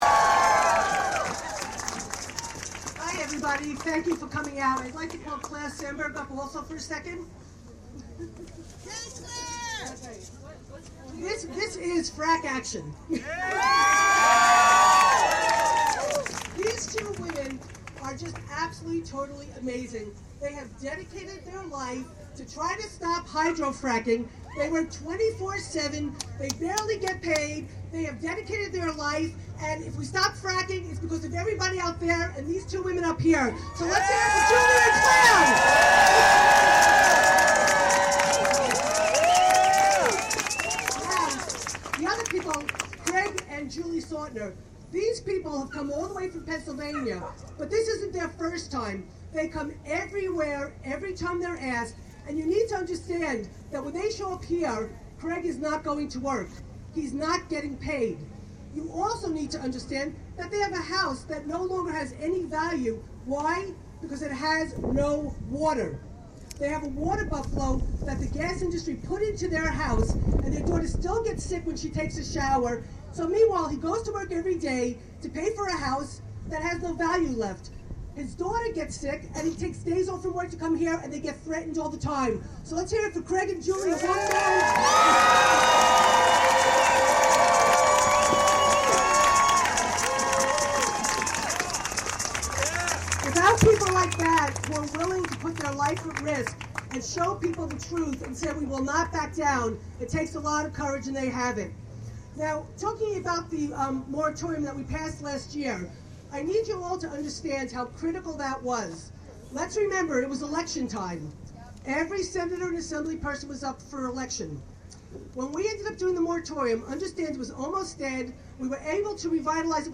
Wave Farm | Susan Zimet, Ulster County legislator, speaking at Albany Earth Day rally May 2.